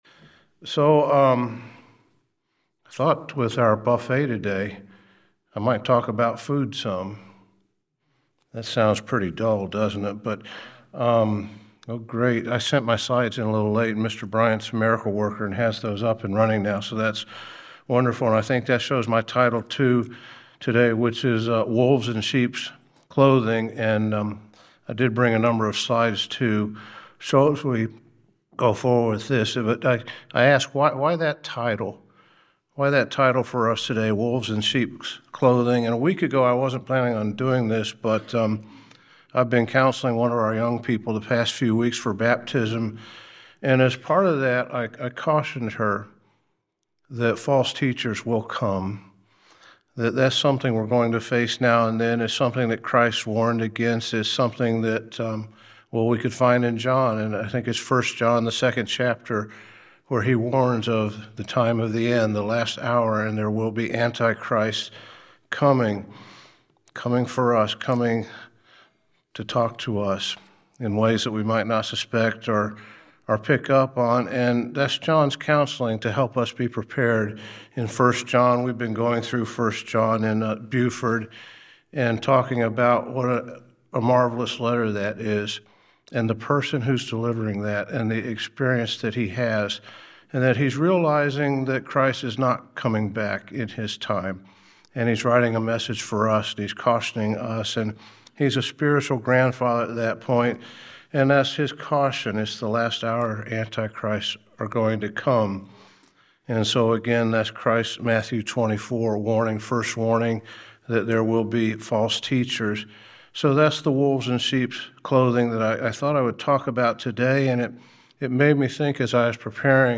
Among the false doctrines that some have brought is that Christians no longer need to follow the clean and unclean principles that are shown in God's laws. This message lists many of the most common scriptures that are used to try to support their arguments and why their statements are inaccurate.